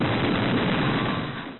explosion-high.mp3